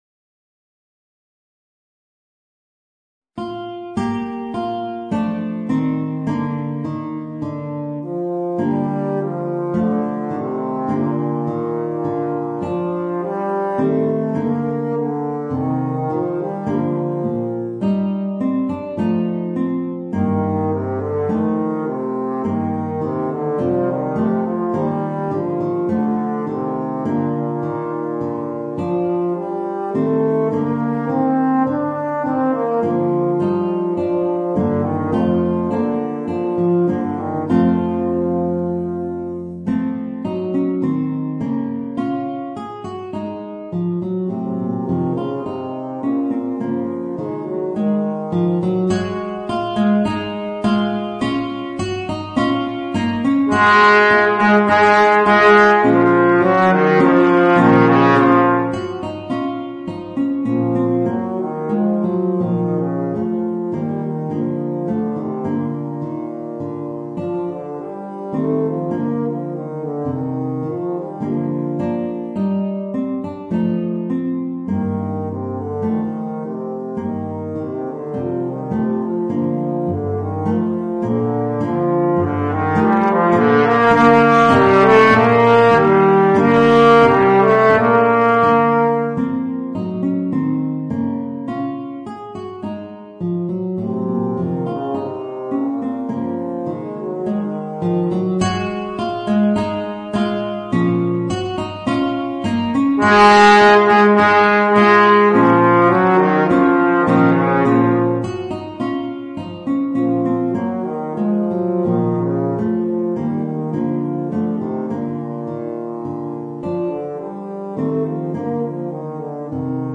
Voicing: Guitar and Bass Trombone